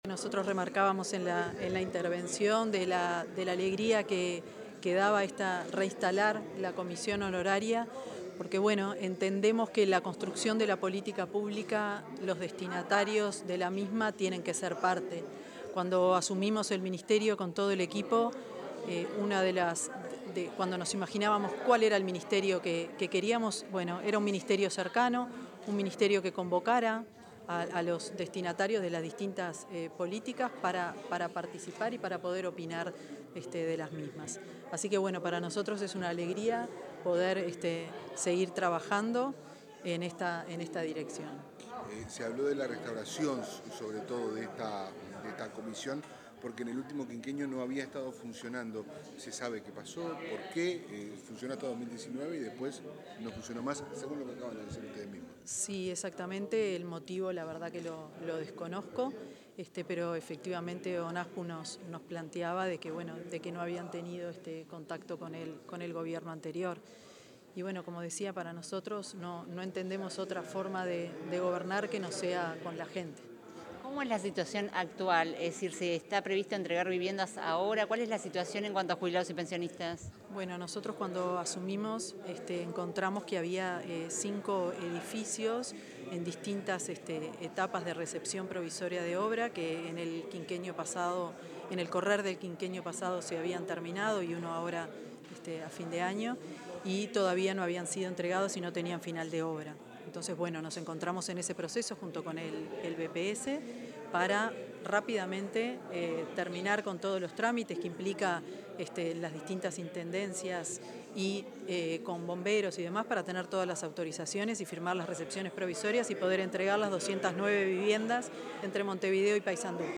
Declaraciones de la ministra de Vivienda y Ordenamiento Territorial, Tamara Paseyro
La ministra de Vivienda y Ordenamiento Territorial, Tamara Paseyro, realizó declaraciones en oportunidad de la reinstalación de la Comisión Honoraria